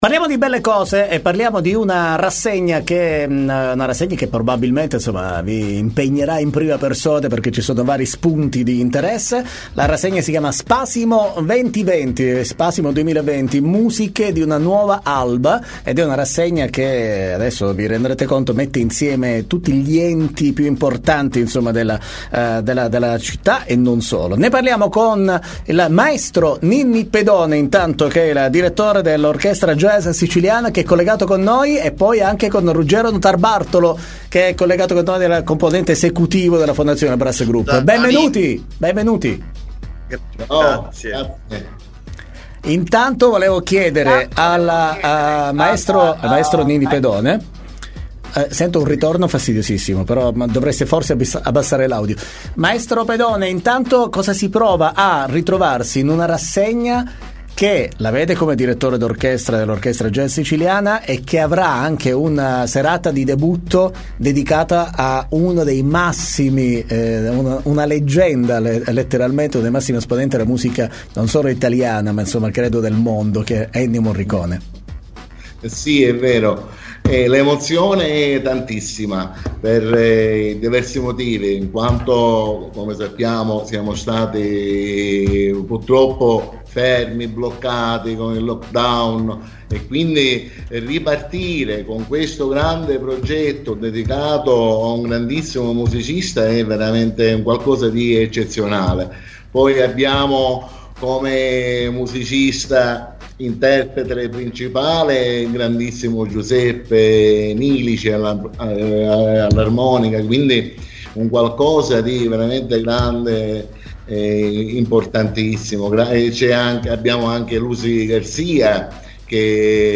Doc Time intervista